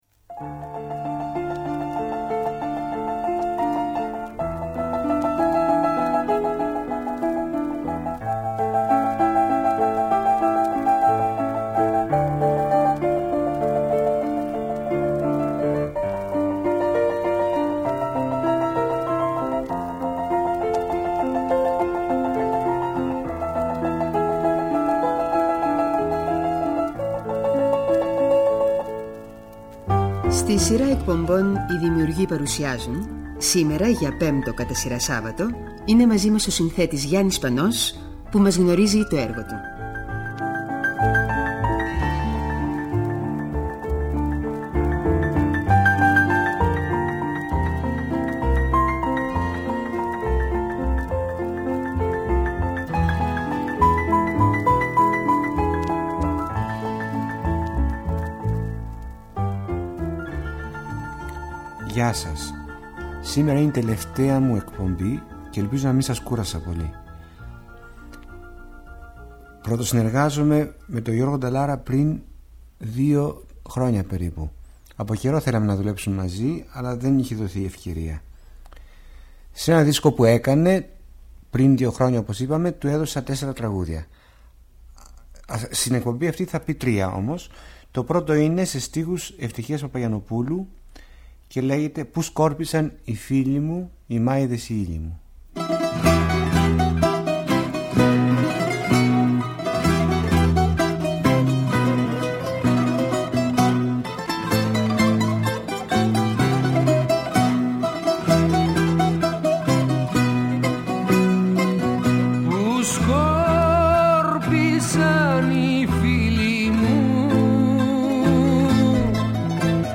Είναι ένα από τα σπάνια ντοκουμέντα του Αρχείου της ΕΡΑ, όπου ακούμε τον Γιάννη Σπανό να αφηγείται τη ζωή του.